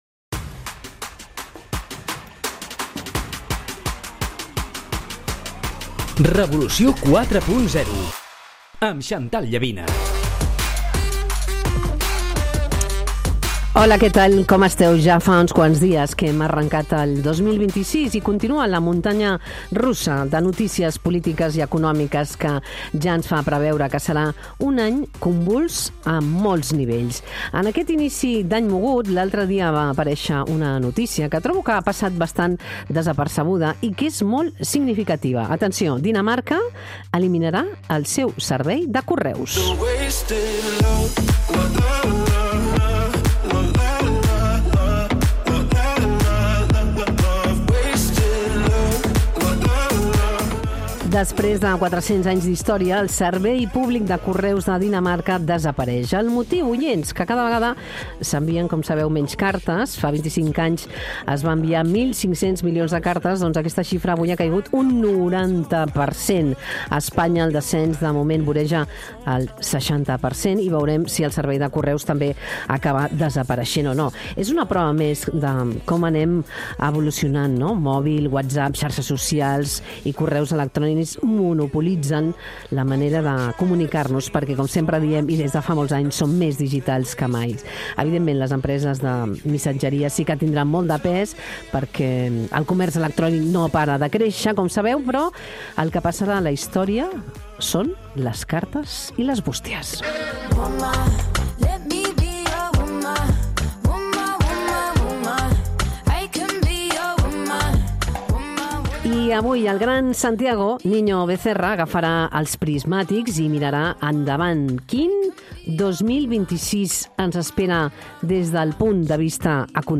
El "Revolució 4.0", dirigit i presentat per Xantal Llavina, comença dissabte amb la primera masterclass de l'any de l'economista Santiago Niño-Becerra, que analitza el 2026 com un any de transició i d'inici del període de la intel·ligència artificial. La conversa aborda el context internacional, amb especial atenció als Estats Units, Veneçuela i la creixent divisió del món en blocs d'influència.